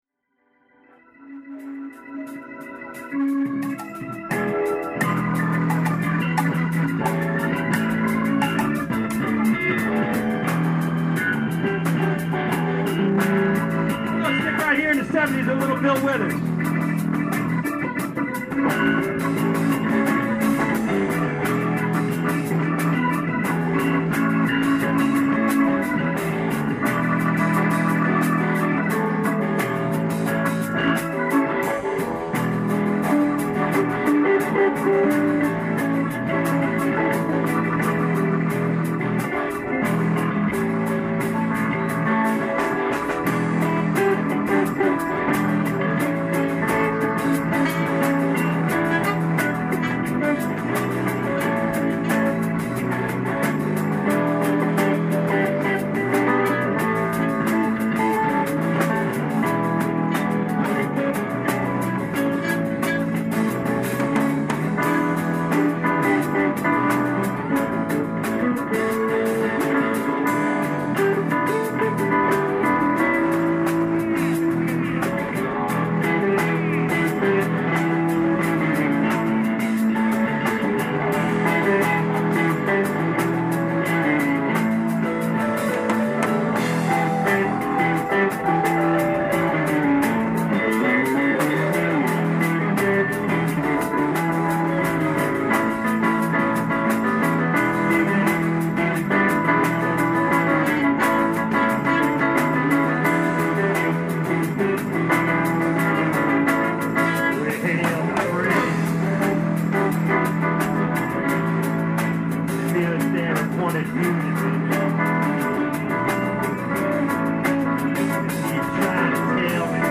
Ocean City, New Jersey
guitar, vocals
keyboards, backing vocals
bass, vocals
drums
sax, flute, vocals